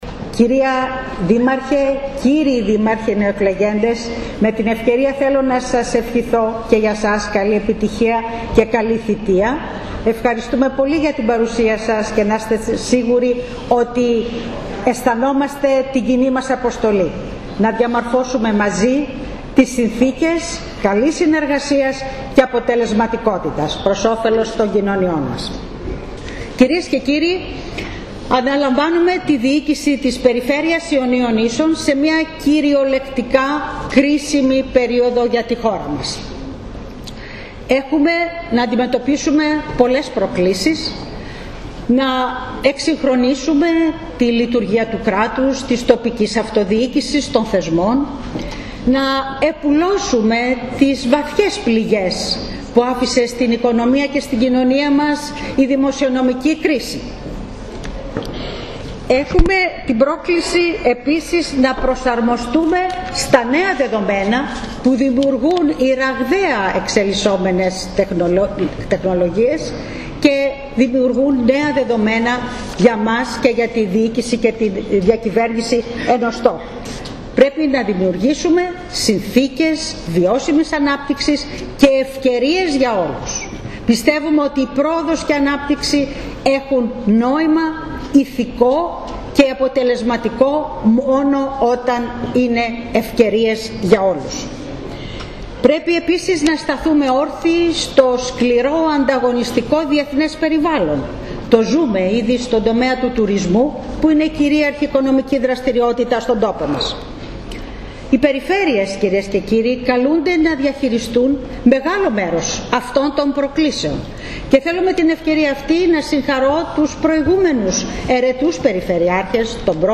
Παρουσία των τοπικών αρχών και πλήθος κόσμου πραγματοποιήθηκε στην Ιόνιο Ακαδημία η χθεσινή ορκωμοσία της Ρόδης Κράτσα και του νέου Περιφερειακού Συμβουλίου Ιονίων Νήσων.
Στην τελετή χοροστάτησε ο Σεβ. Μητροπολίτης Νεκτάριος, ο οποίος ευχήθηκε καλή επιτυχία και δύναμη στη νέα Περιφερειάρχη.